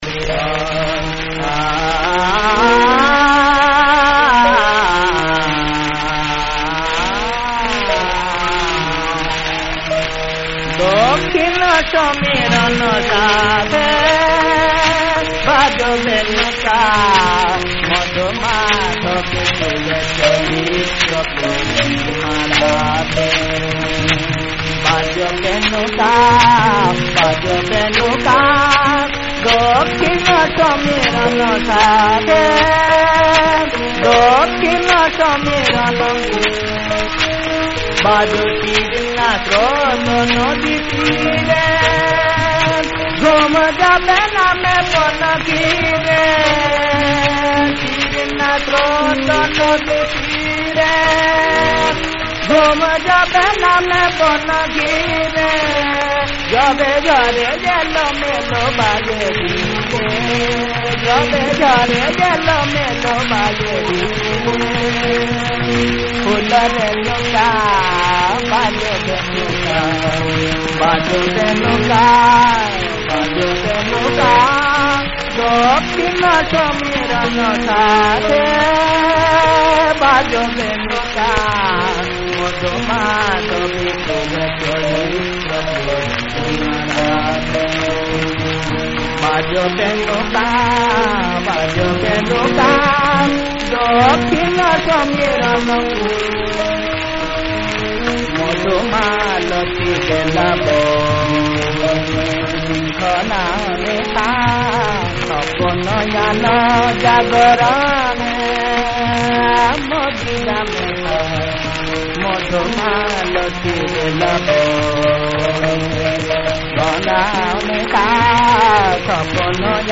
মধুমাধবী সারং-ত্রিতালী।